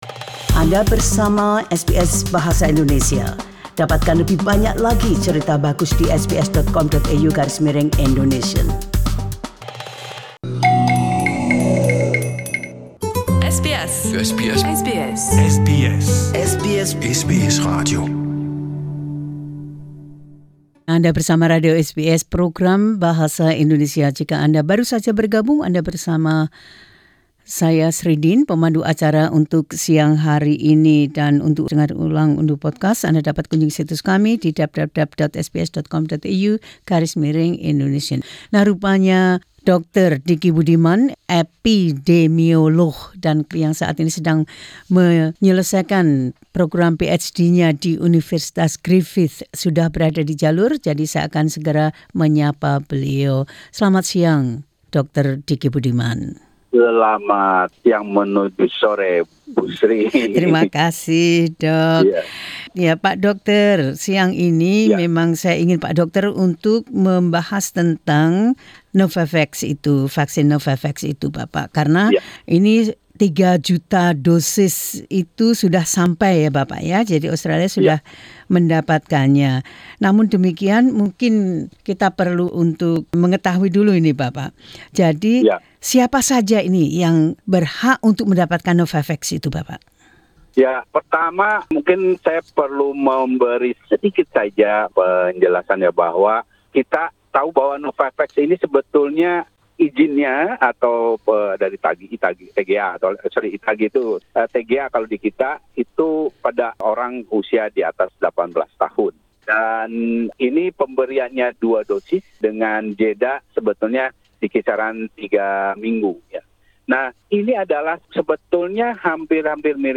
Epidemiologist answering questions about Novavax vaccine